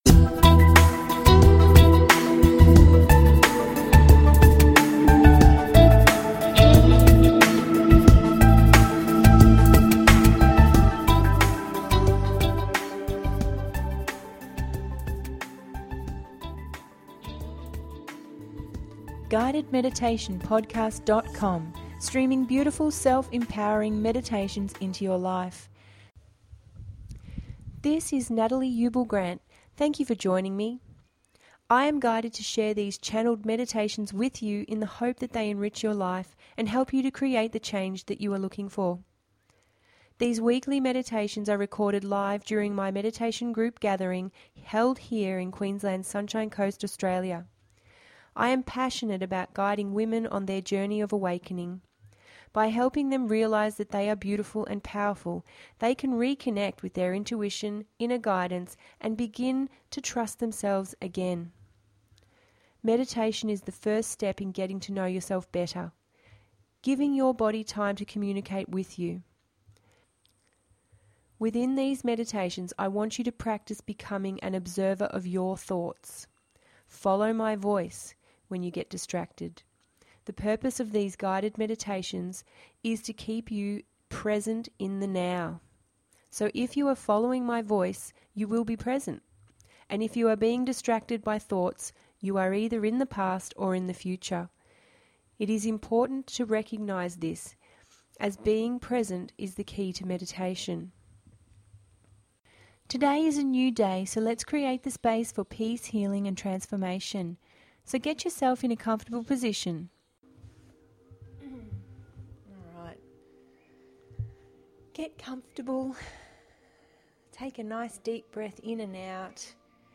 009-clearing-tension-guided-meditation-podcast.mp3